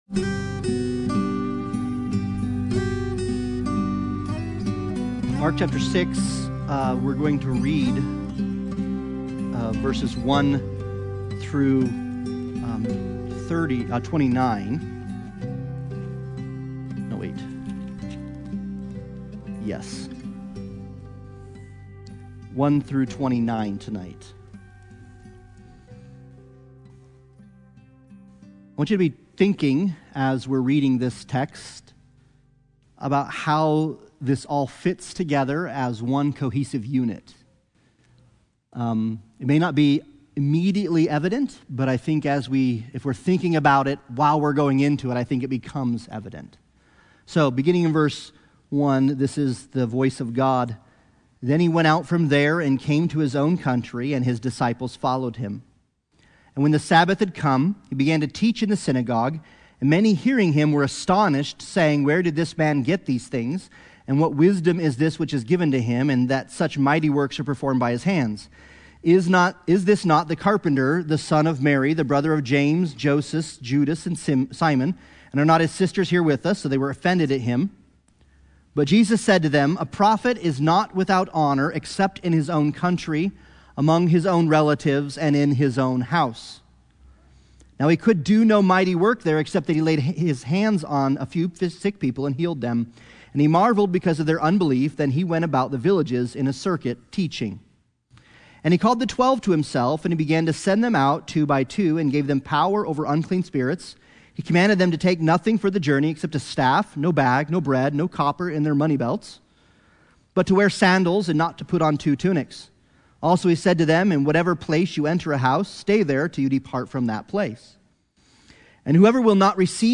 Passage: Mark 6:1-29 Service Type: Sunday Bible Study « Danger & Delight